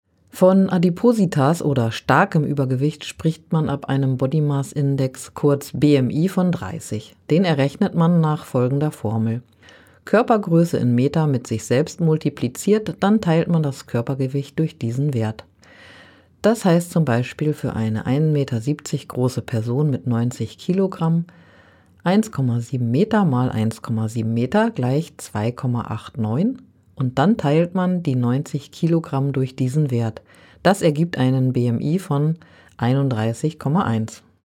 O-Töne20.08.2025